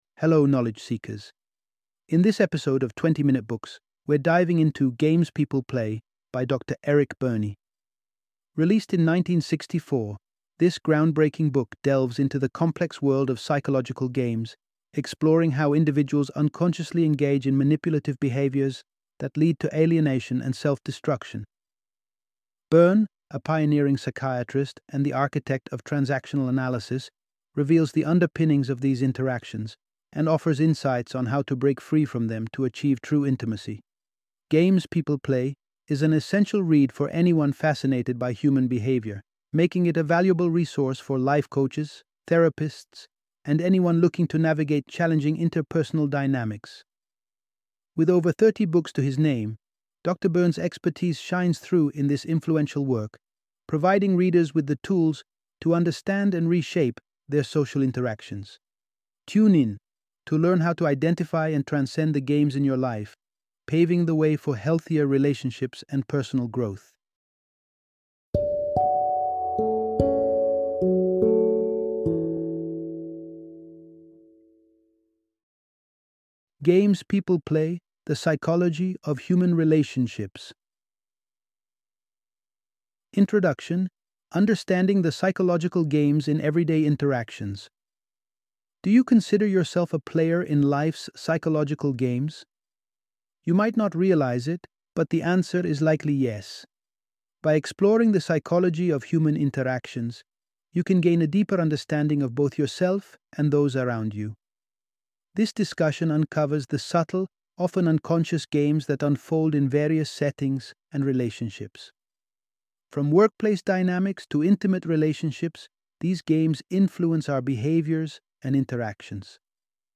Games People Play - Audiobook Summary